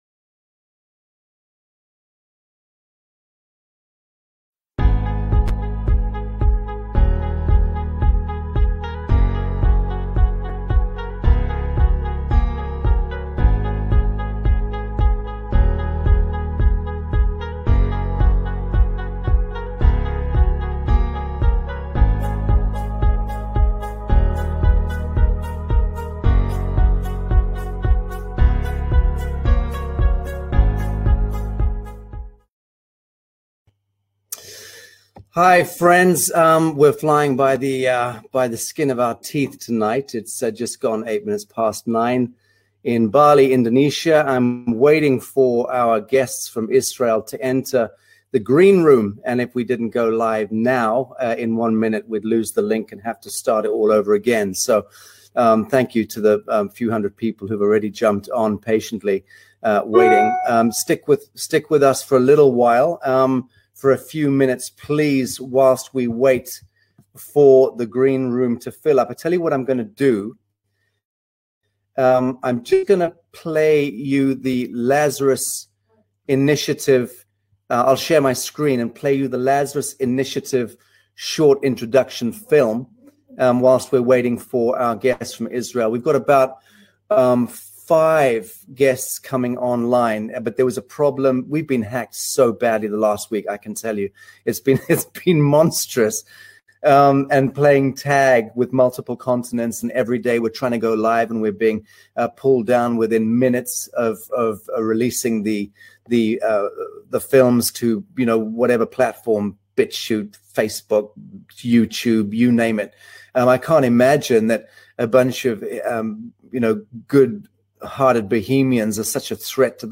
A panel of experts on the situation in Israel.